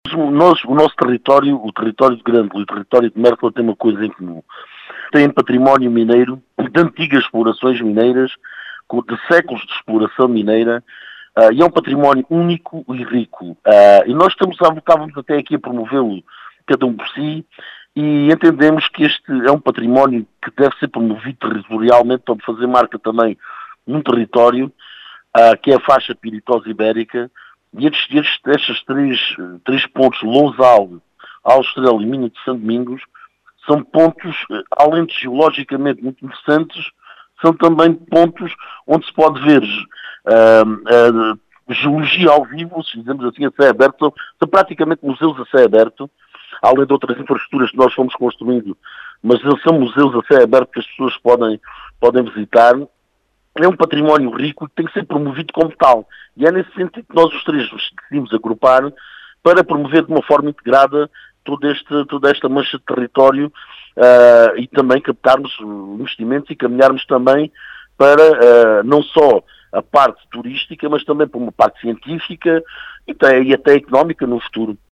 As explicações foram deixadas à Rádio Vidigueira, por Carlos Teles, presidente da Câmara Municipal de Aljustrel, que fala em verdadeiros “museus a céu aberto”.